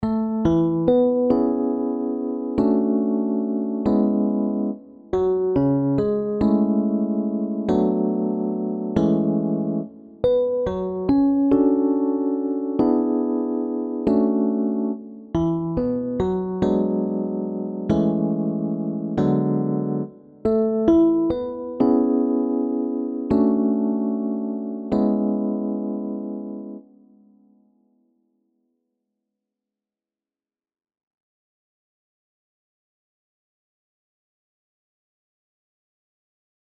Licht jazzy inzingoefening II
Nu is echter de omvang voor de verschillende stemmen een stuk minder groot:
Inzingoefening-3-4-A-Jazzy-oefening-met-maj79.mp3